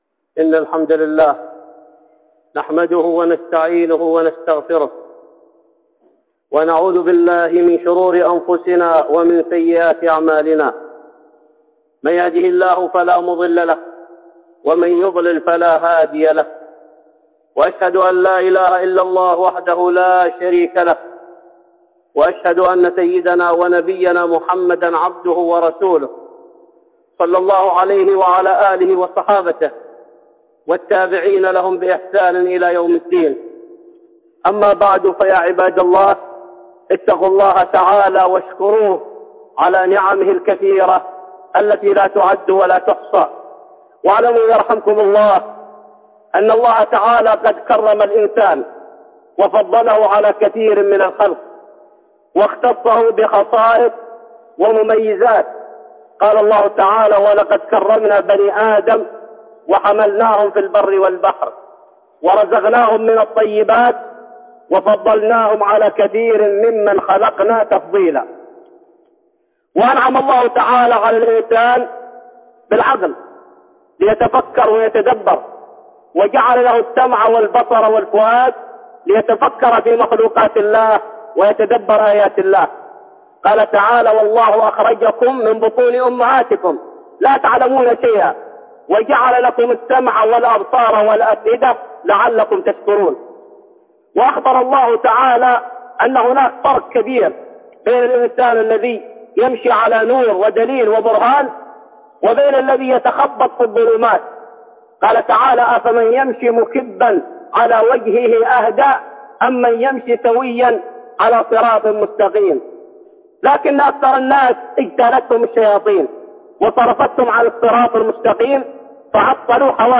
(خطبة جمعة) من خصال الجاهلية